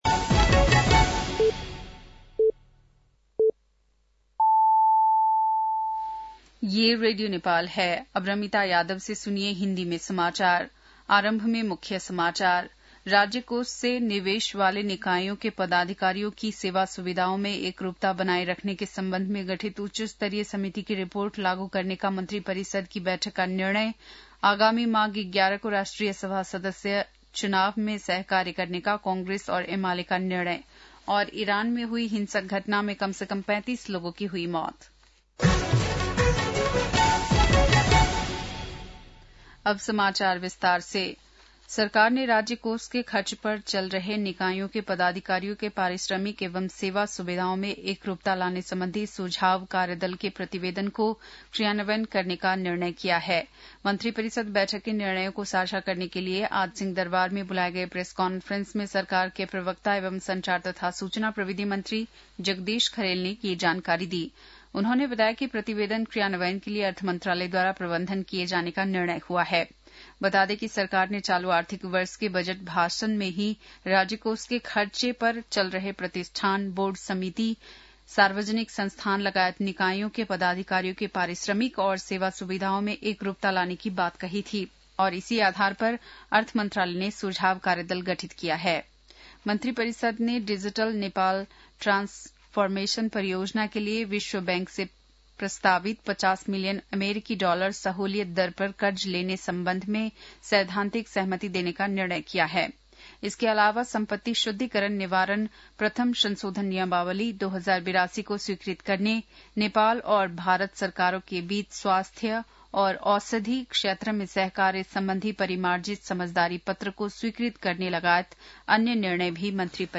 बेलुकी १० बजेको हिन्दी समाचार : २२ पुष , २०८२
10-pm-hindi-news-9-22.mp3